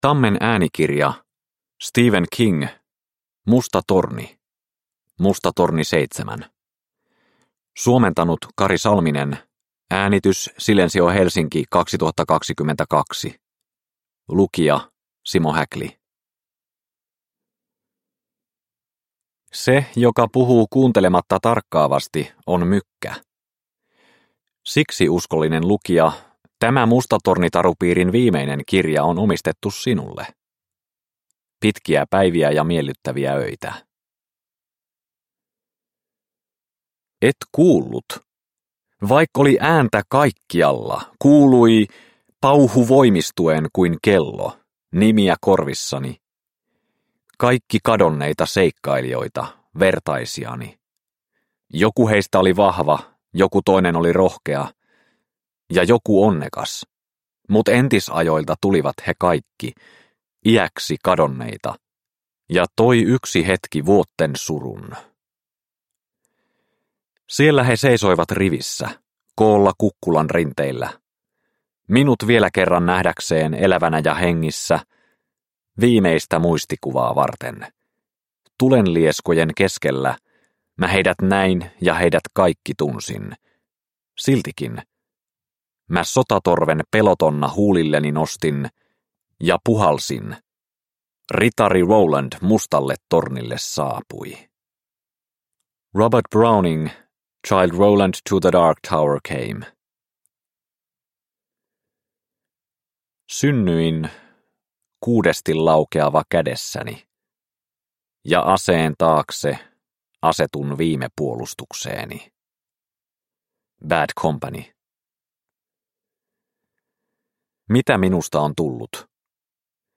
Musta torni – Ljudbok – Laddas ner